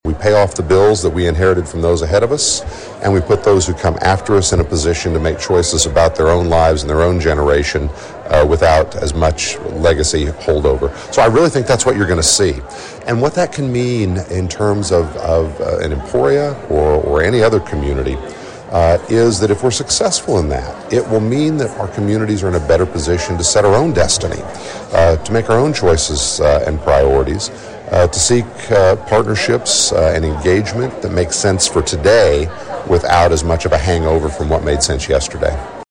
Among those in attendance was recently-elected US House Representative Derek Schmidt. He tells KVOE News the federal economic focus is “right-sizing” government as the changeover continues from Joe Biden to Donald Trump.